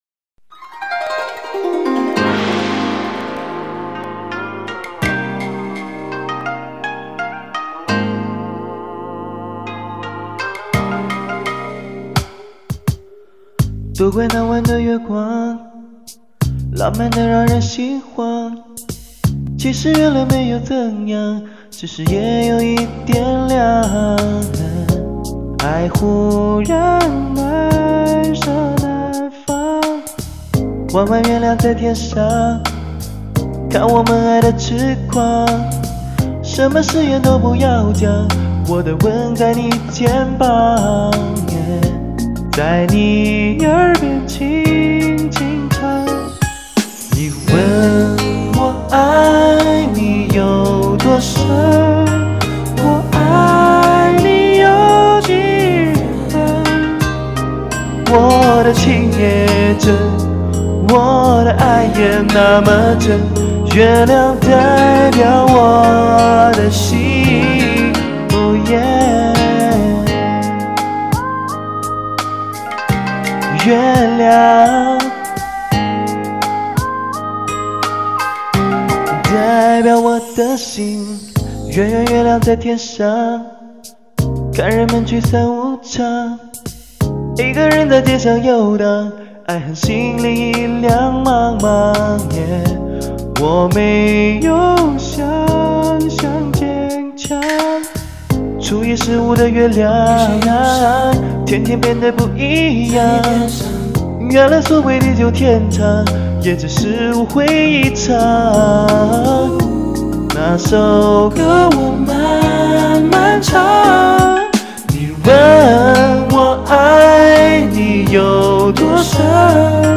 但是我学会了用COOLEDIT